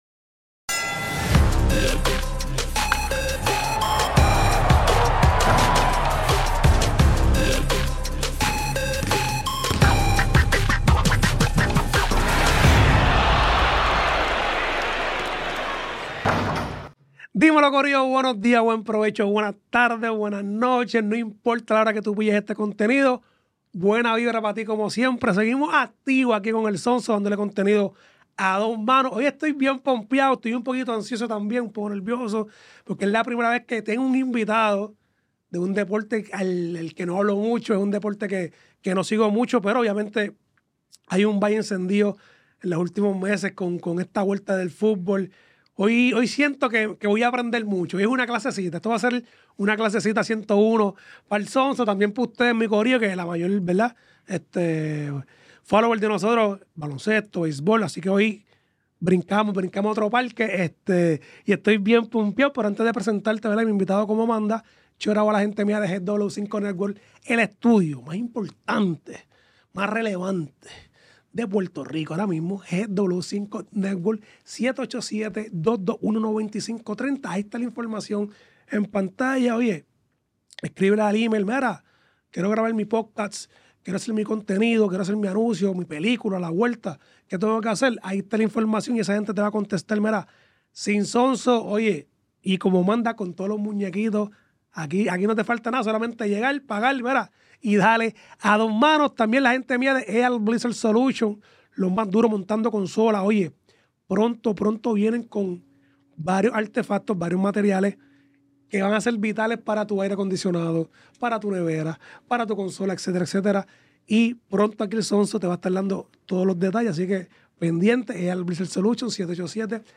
Grabado en GW5 Studio